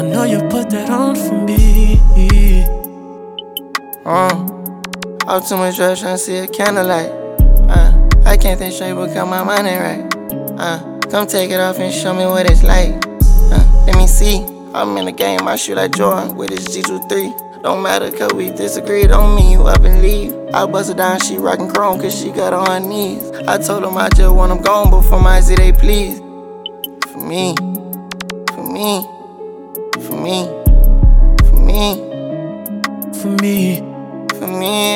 Жанр: Иностранный рэп и хип-хоп / R&b / Соул / Рэп и хип-хоп